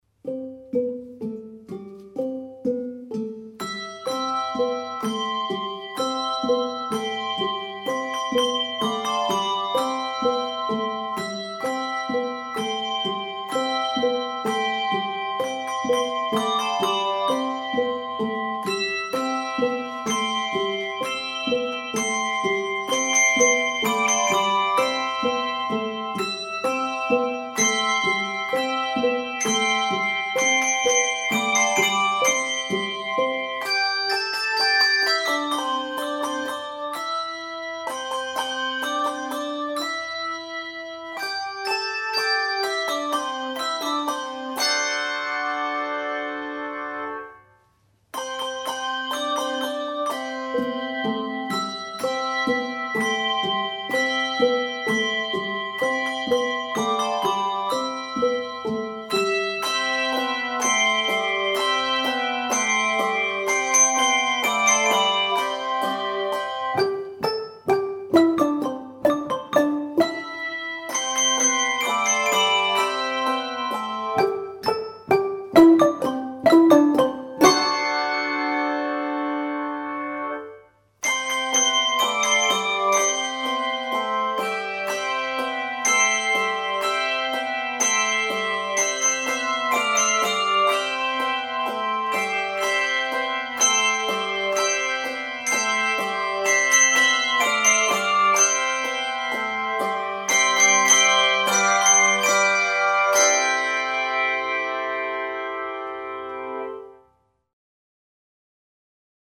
Voicing: Handbells 3-4 Octave